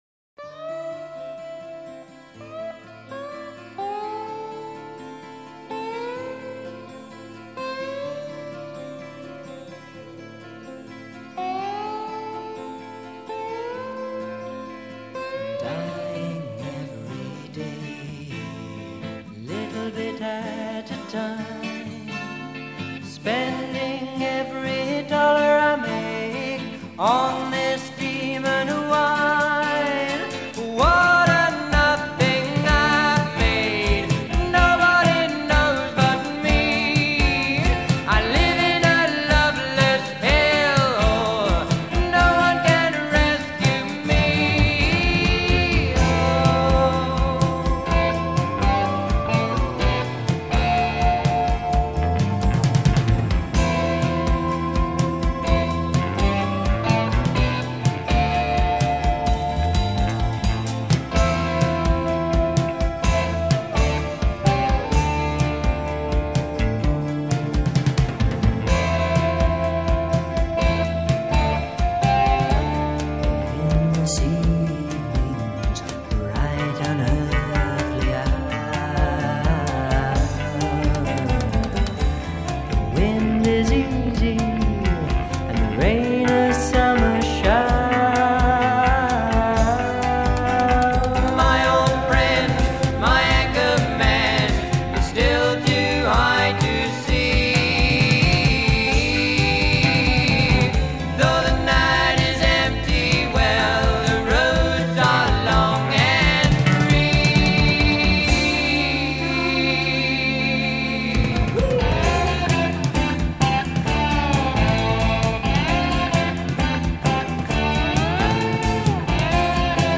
*** (prog)